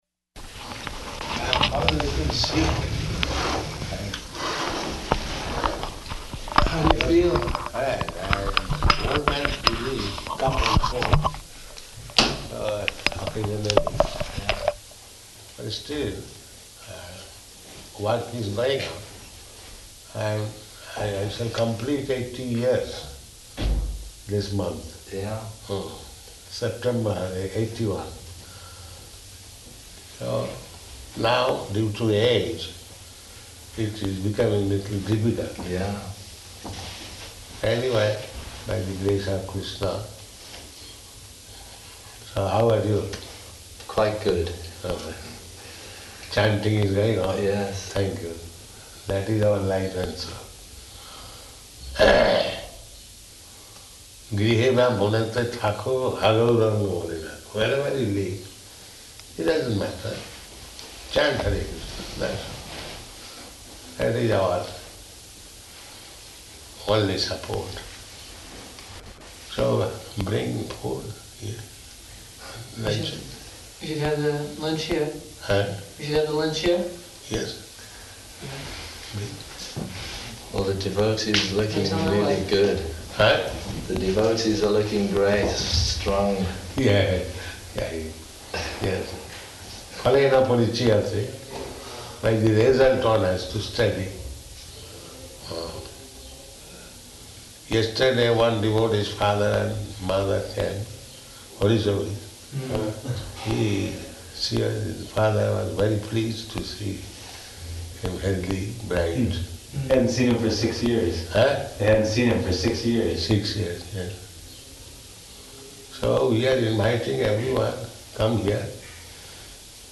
Conversation with George Harrison --:-- --:-- Type: Conversation Dated: July 26th 1976 Location: London Audio file: 760726R1.LON.mp3 [Prabhupāda is very hoarse; he is conducting the conversation from his bed] George Harrison: How do you feel?